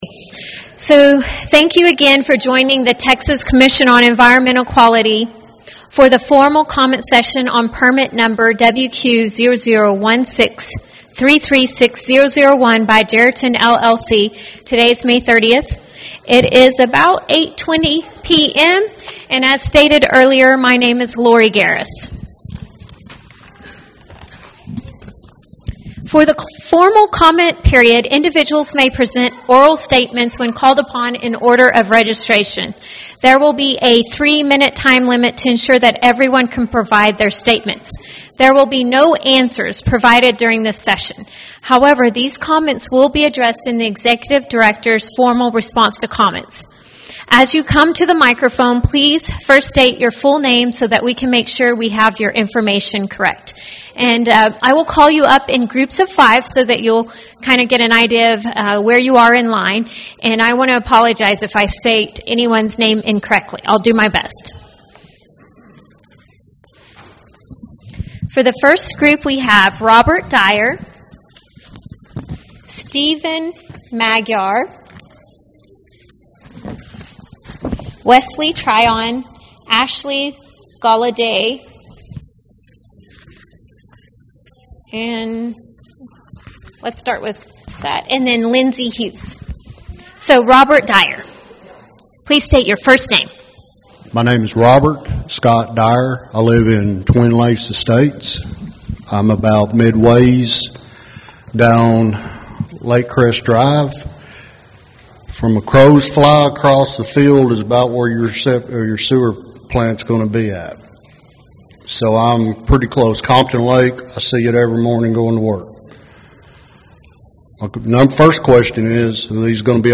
2024-1696-MWD - Public Meeting Audio